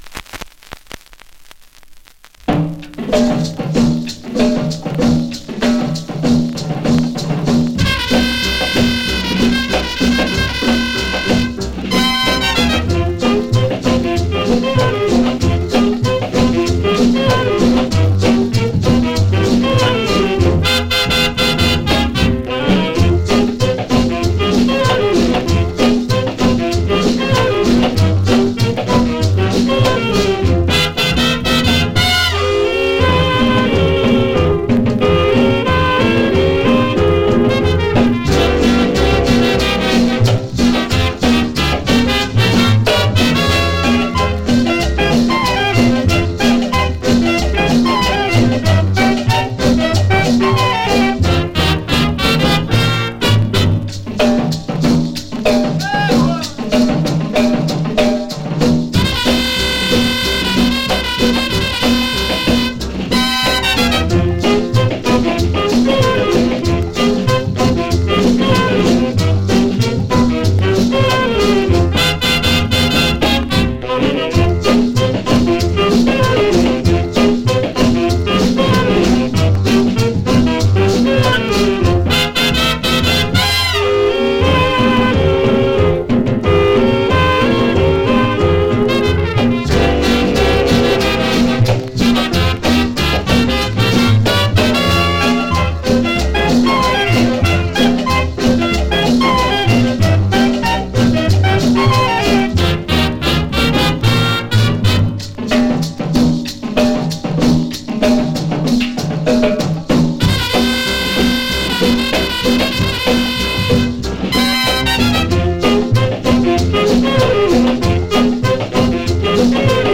Day 11: Cumbia con Jazz